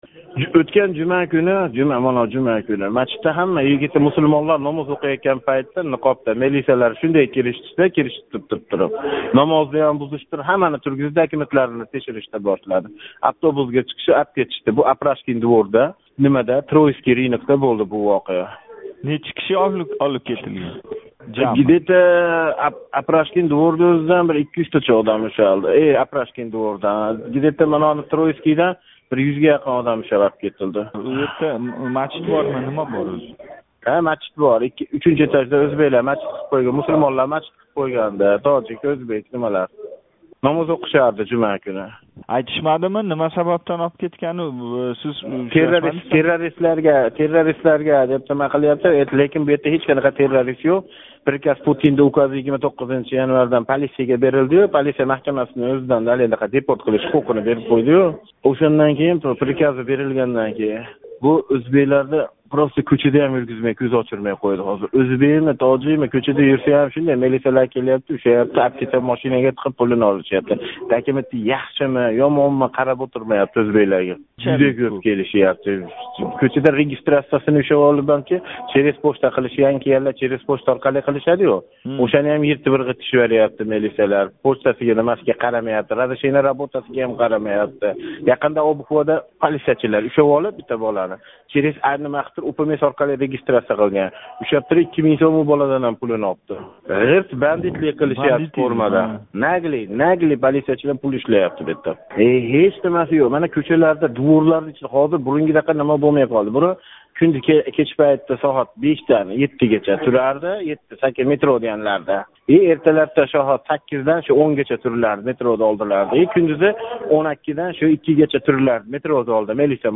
Исмини айтмасликни сўраган йигит билан суҳбат